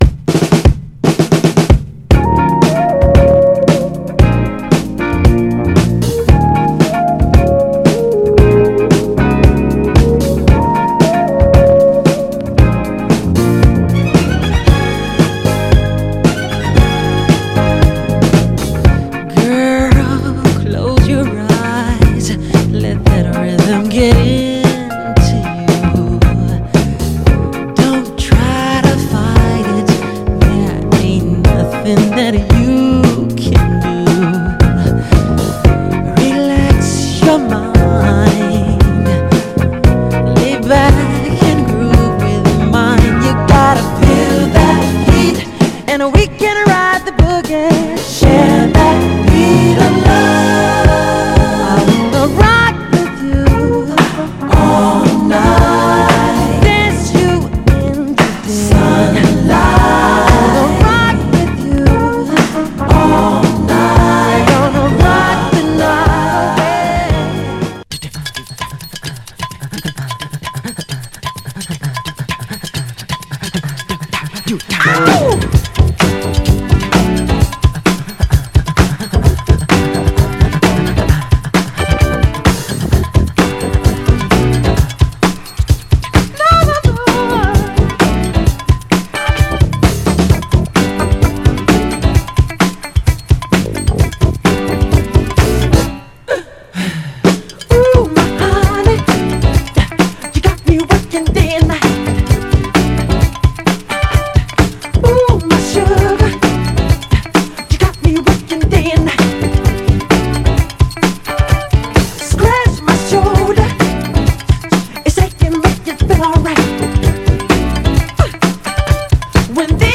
世界中のどこでかかっても大合唱の、みんな大好き特大ダンス・クラシック/モダン・ダンサーの大ヒット作です！
盤は少しジュークボックス跡ありますが、グロスが残っておりプレイ良好です。
※試聴音源は実際にお送りする商品から録音したものです※